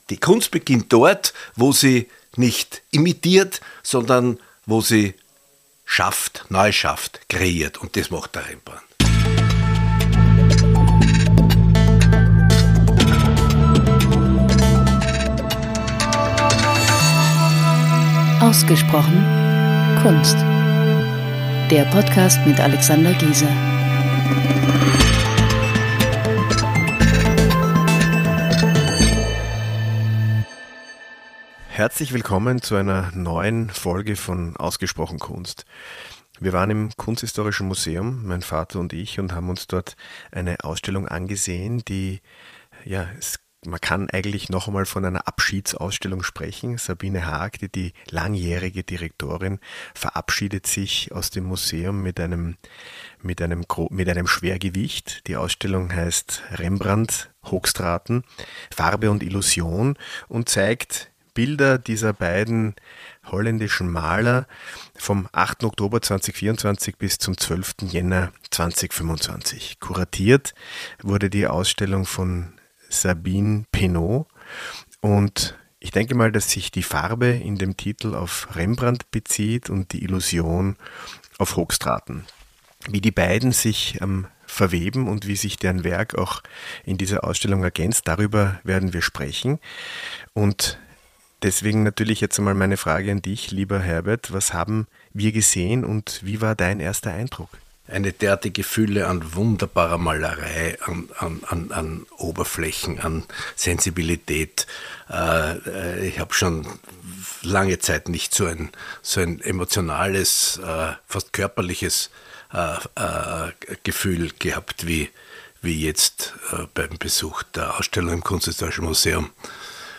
Mein Vater und ich haben gestaunt, analysiert und darüber gesprochen.